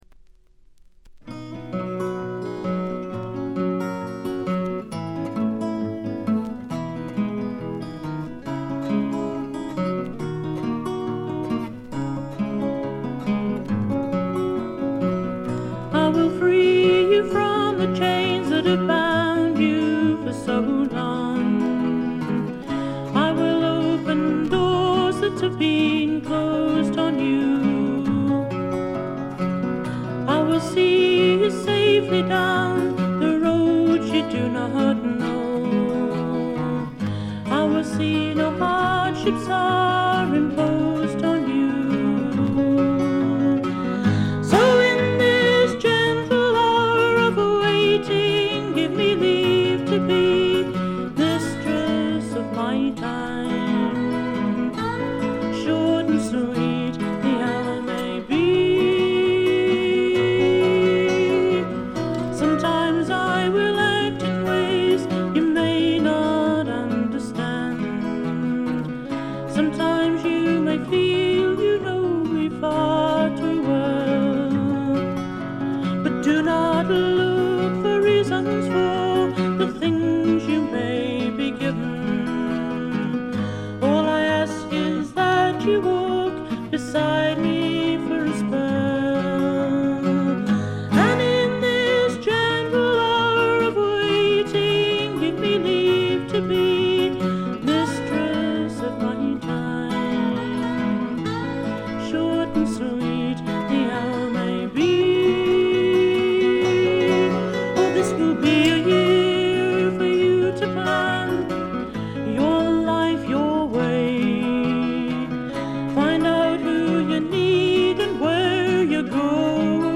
これ以外は軽微なバックグラウンドノイズ程度です。
日本ではほとんど語られることのない英国の女性フォーキー／シンガー・ソングライター。
試聴曲は現品からの取り込み音源です。
vocal and guitar